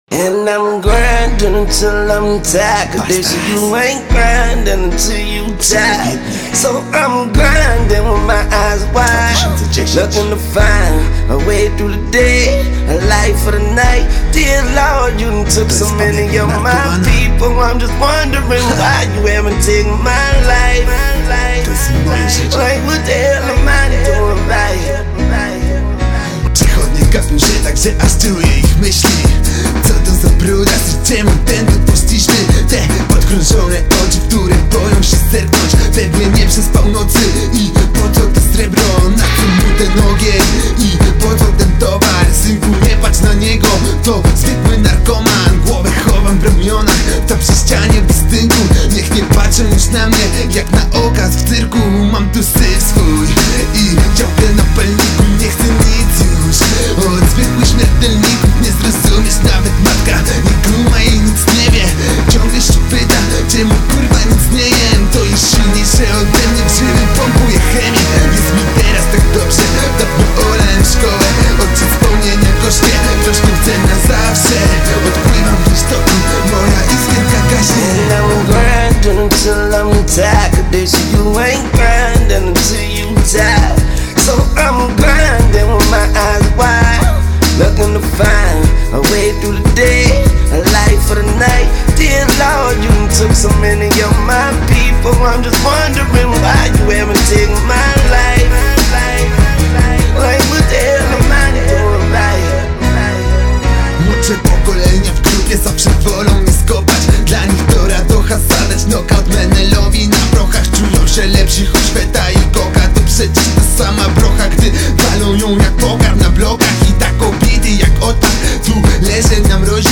hip-hop, rap